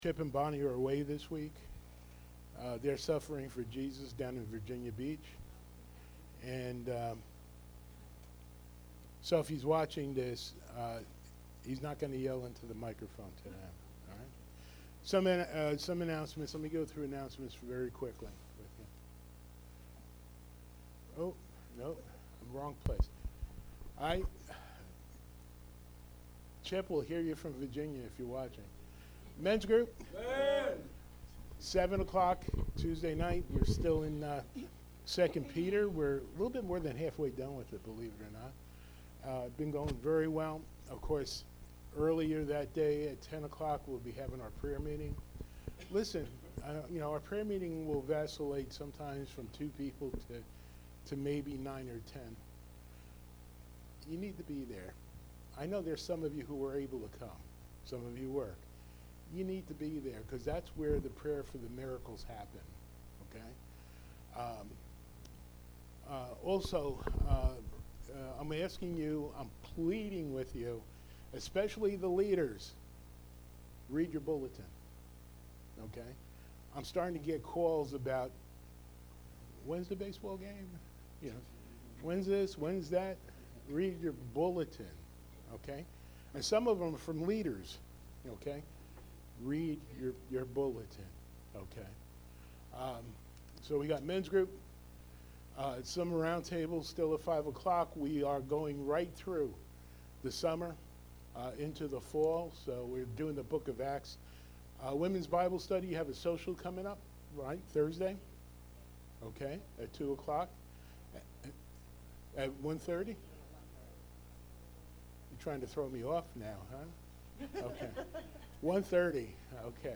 Series: Sunday Morning Worship Service